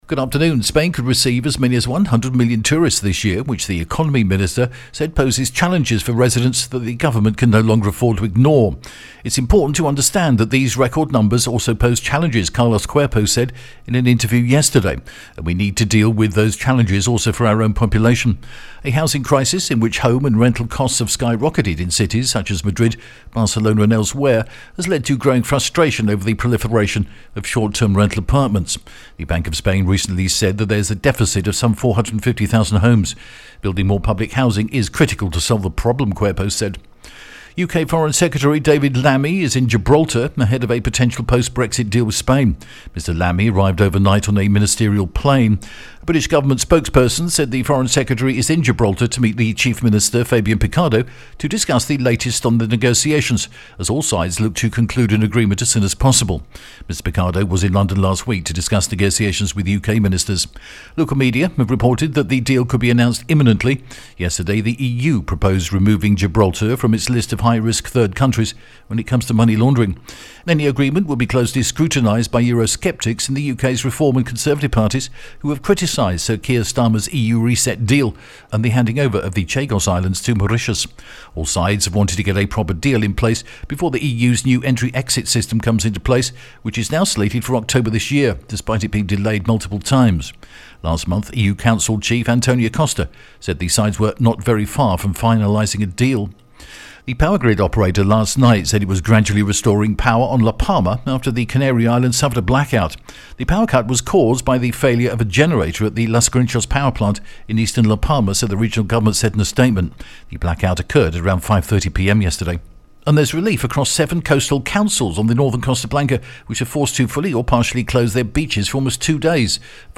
breaking Spanish news.